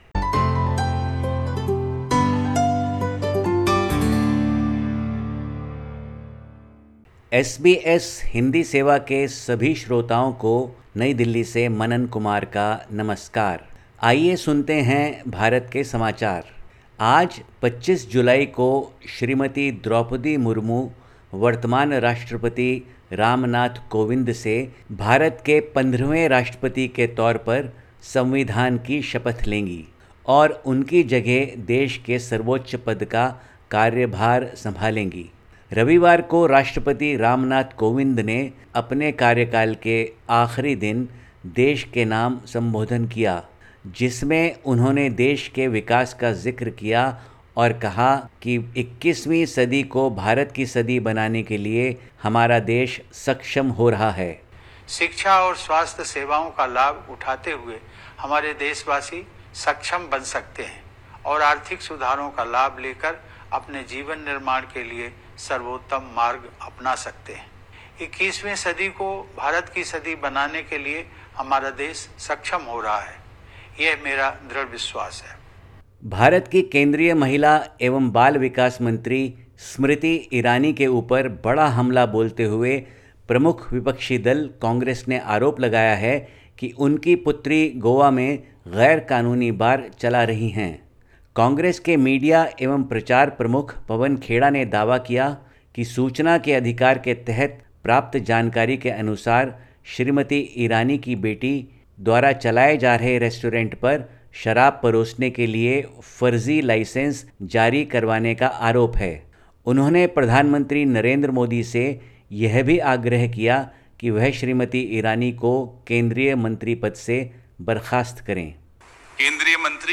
Listen to the latest SBS Hindi report from India. 25/07/2022